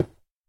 stone3.mp3